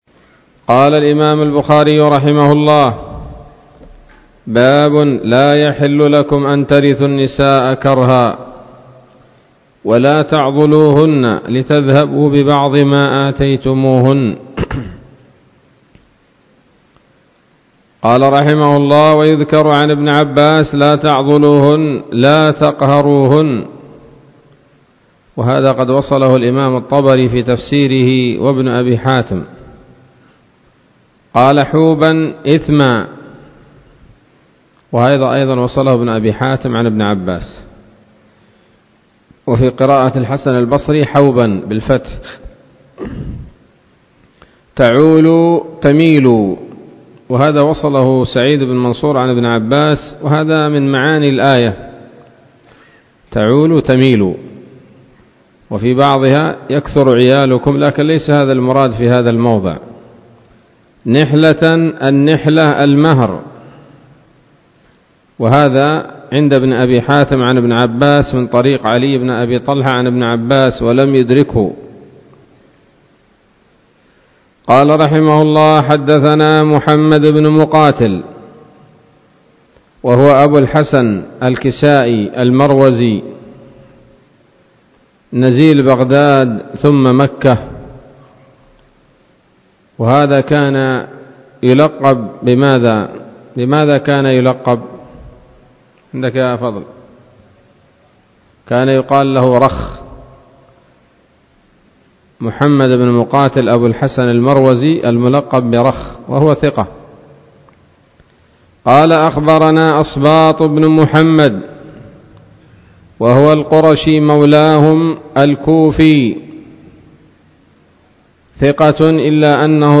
الدرس السابع والستون من كتاب التفسير من صحيح الإمام البخاري